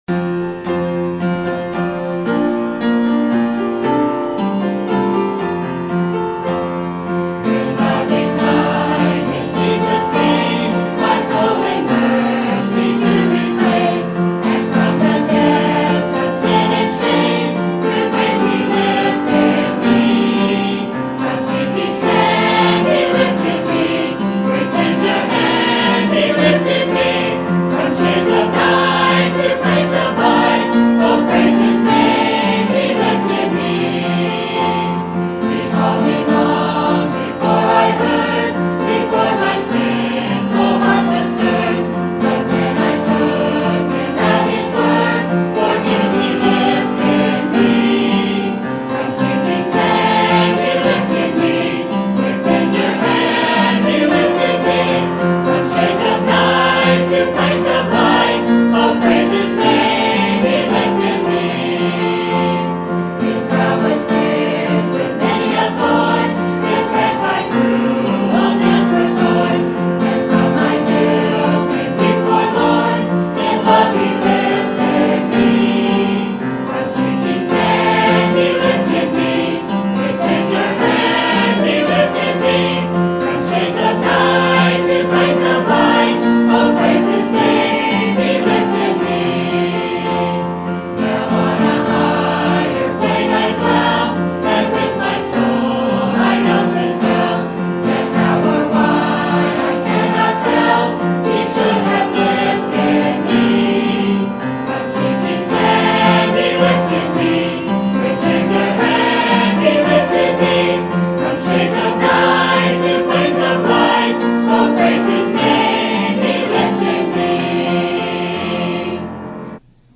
Sermon Topic: Salt and Light Sermon Type: Series Sermon Audio: Sermon download: Download (33.93 MB) Sermon Tags: Matthew Salt Judge Righteousness